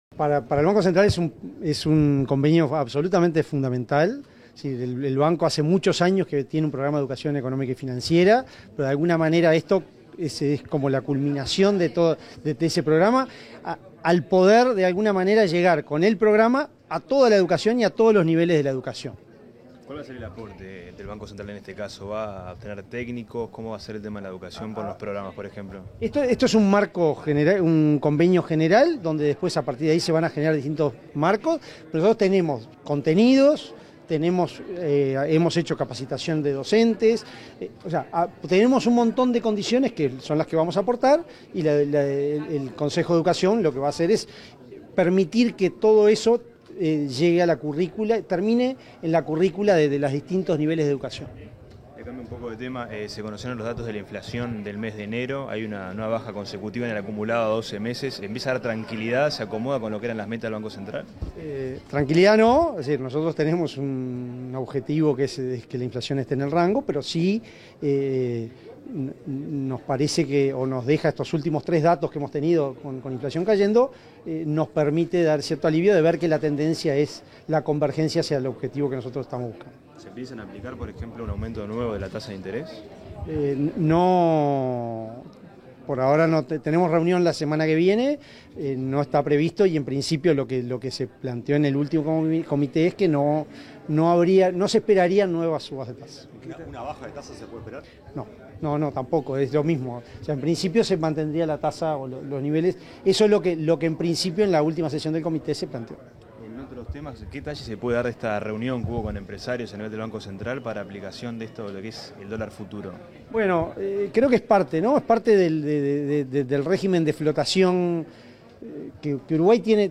Declaraciones del presidente del Banco Central del Uruguay, Diego Labat
Declaraciones del presidente del Banco Central del Uruguay, Diego Labat 07/02/2023 Compartir Facebook Twitter Copiar enlace WhatsApp LinkedIn Tras firmar un convenio marco de cooperación con la Administración Nacional de Educación Pública (ANEP), este 7 de febrero, el presidente del Banco Central del Uruguay, Diego Labat, realizó declaraciones a la prensa.